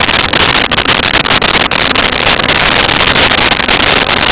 Sfx Amb Tunneltram Loop
sfx_amb_tunneltram_loop.wav